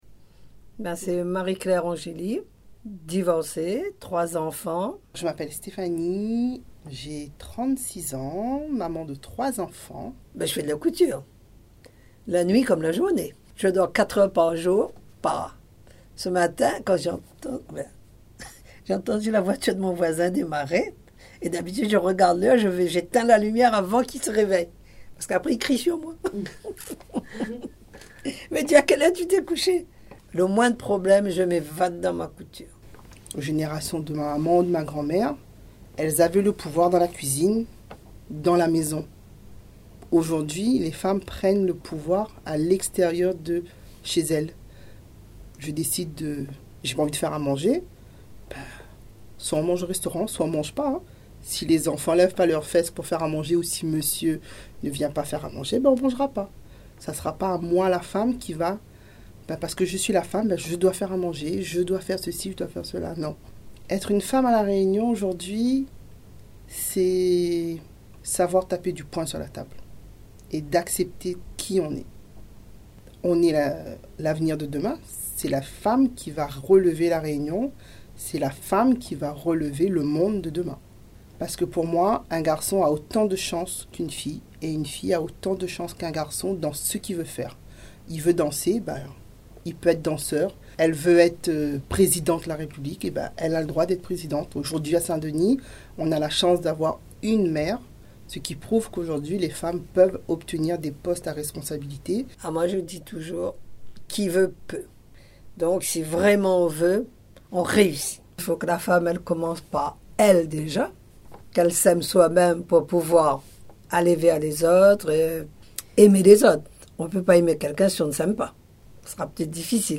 L’interview de deux femmes exceptionnelles